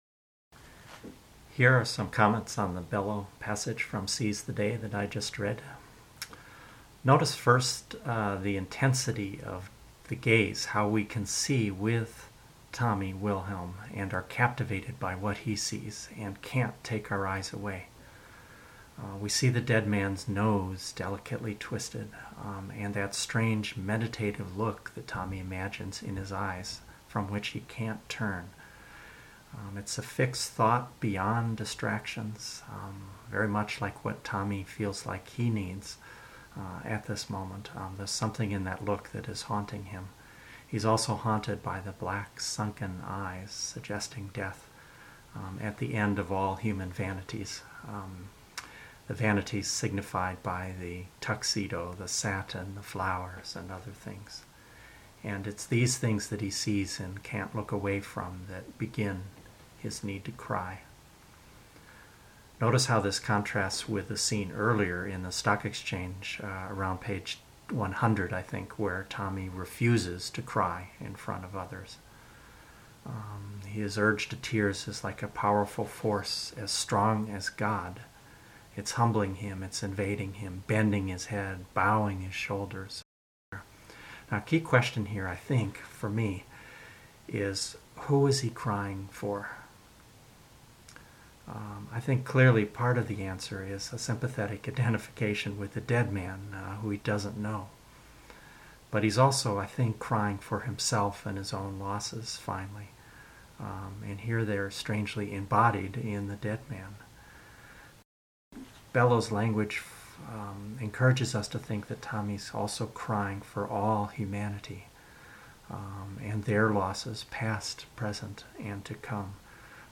An example of what commentary can do. I worked from an outline and practiced once before recording. Playing it back, I realize my commentary is pretty somber, but I guess that matches the tragic mood of Bellow’s story at the end.